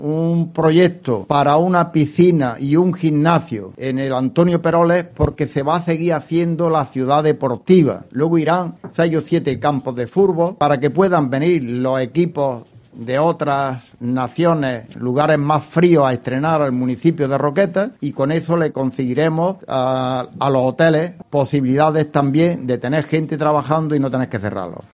La presentación ha tenido lugar en una cafetería del Puerto Deportivo de Aguadulce y han acompañado al candidato